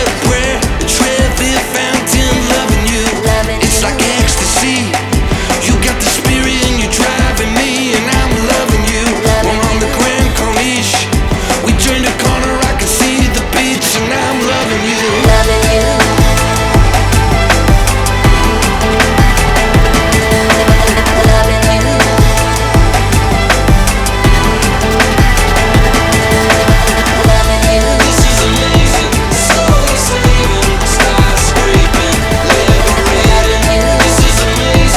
Жанр: Альтернатива
Alternative